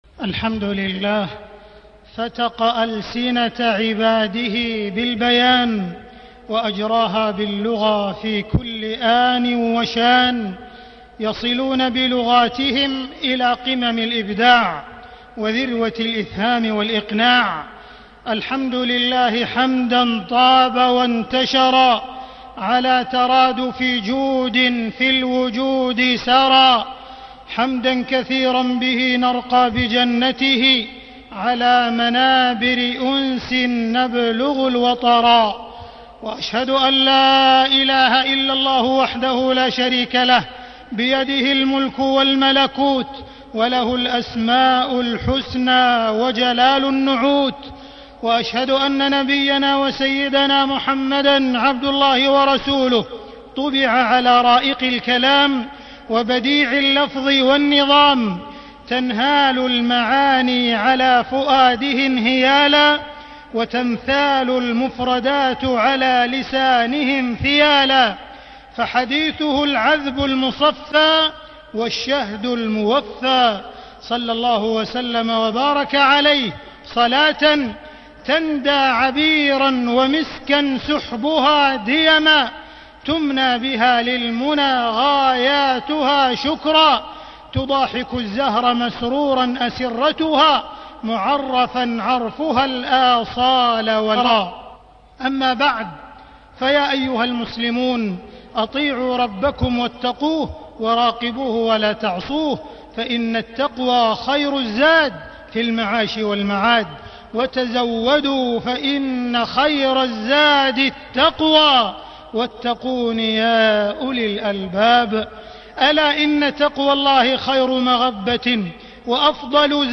تاريخ النشر ٢٧ شوال ١٤٣٣ هـ المكان: المسجد الحرام الشيخ: معالي الشيخ أ.د. عبدالرحمن بن عبدالعزيز السديس معالي الشيخ أ.د. عبدالرحمن بن عبدالعزيز السديس الفخر باللغة العربية